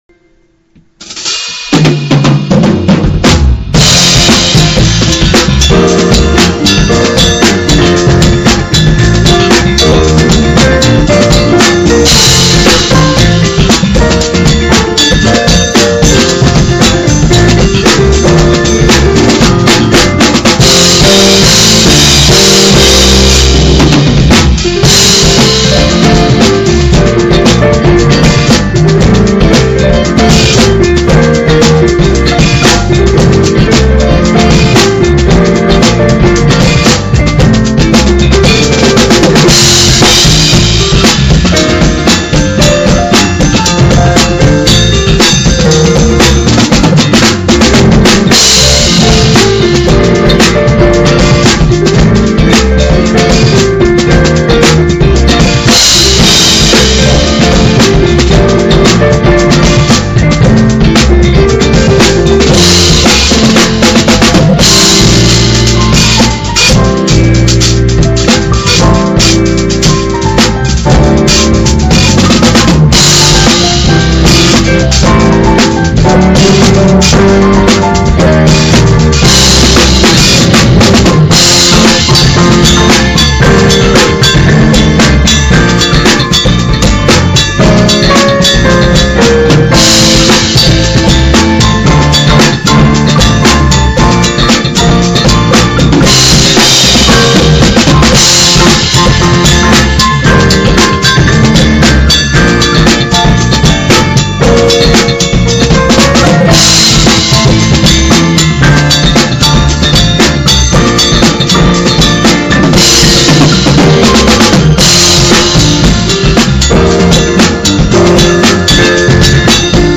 2007-09-14 오전 10:08:00 공연 전에 연습하다가 mp3로 녹음을 해본겁니다. 그냥 합주실에 mp3띡 놓고 한거라 음질은 별로 좋지 않습니다 ㅎ; 색소폰 멤버가 없어서 색소폰 부분을 피아노로 대체..